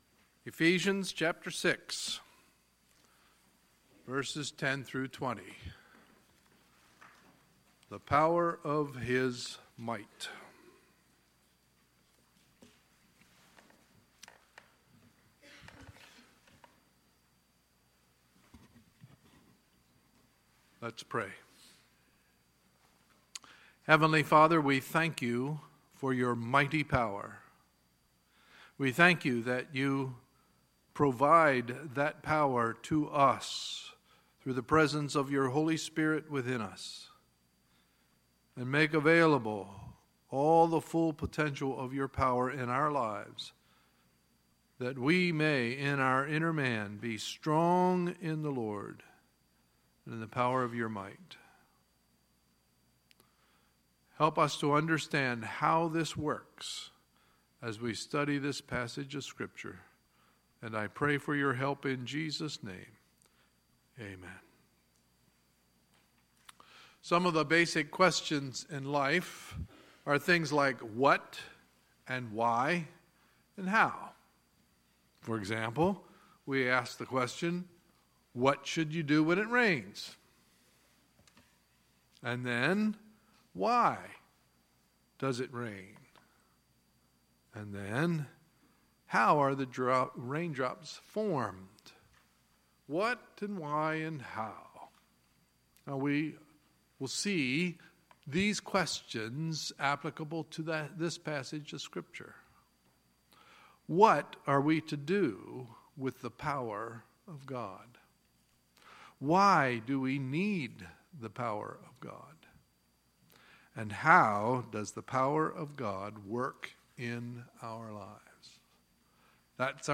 Sunday, August 27, 2017 – Sunday Morning Service